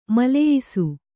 ♪ maleyisu